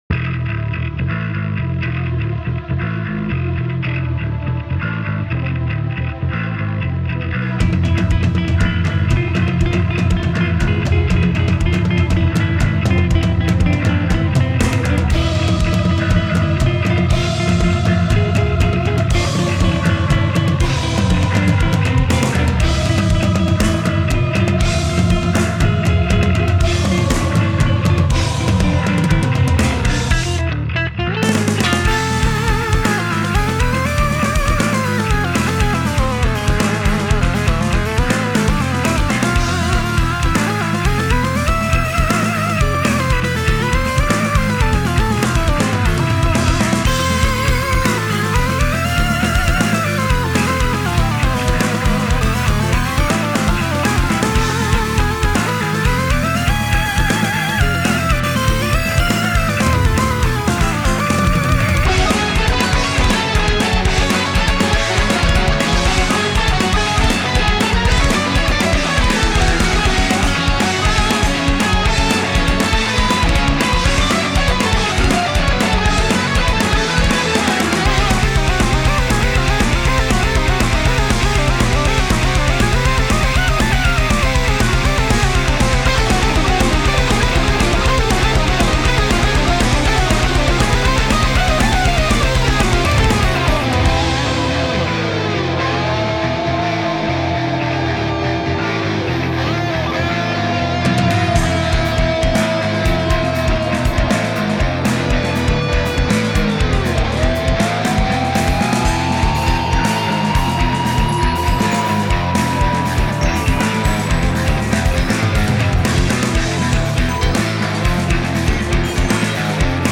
video game remix